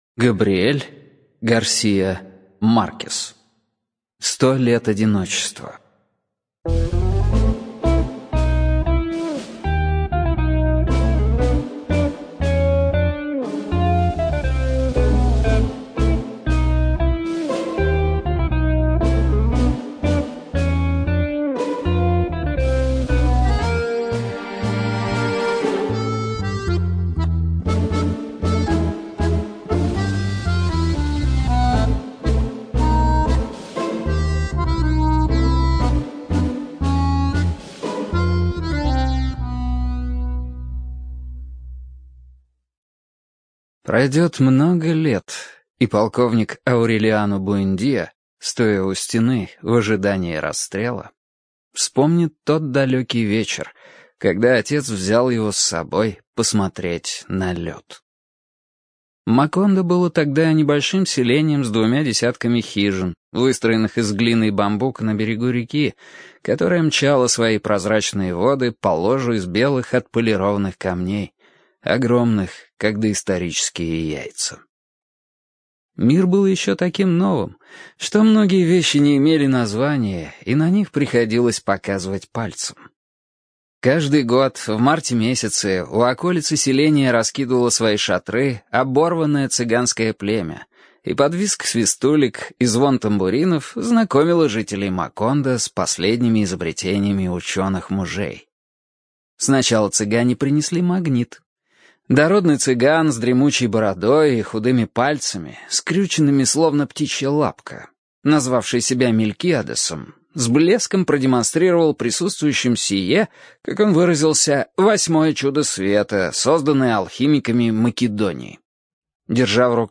ЖанрКлассическая проза